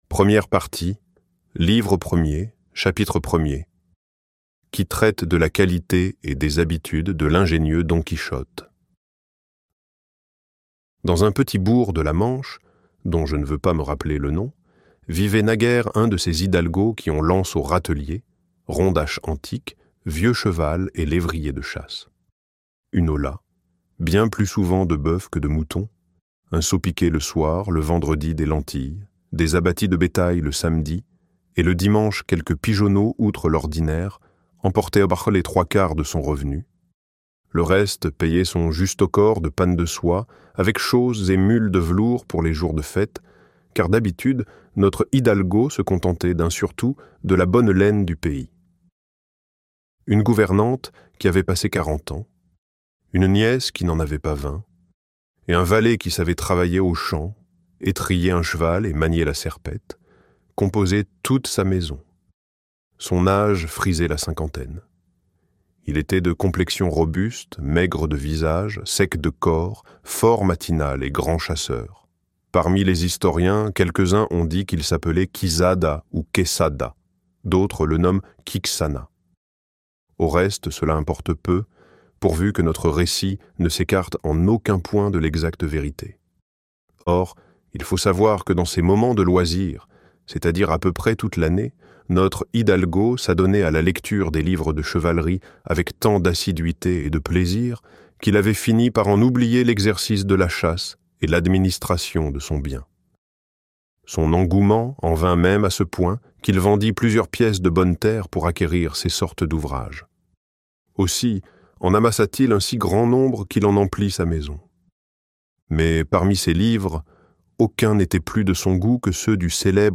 Don Quichotte - Livre Audio